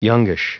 Prononciation du mot youngish en anglais (fichier audio)
Prononciation du mot : youngish
youngish.wav